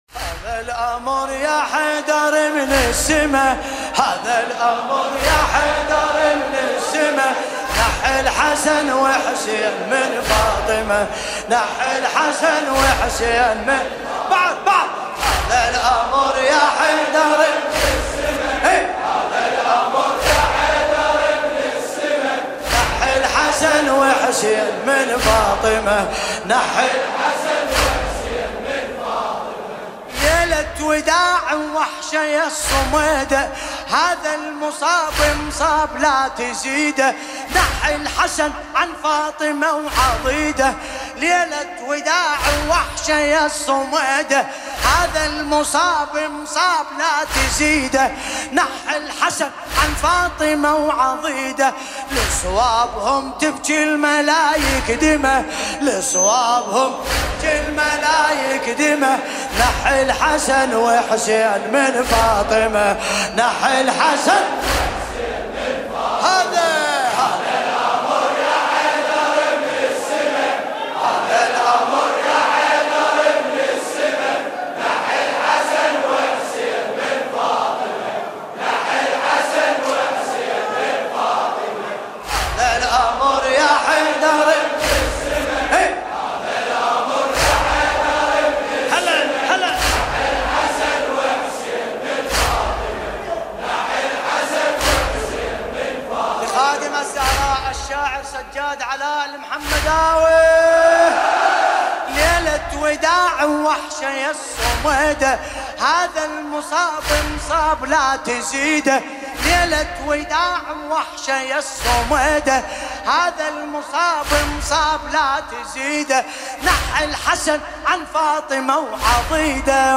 لطمية